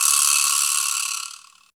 87 VIBRASL-R.wav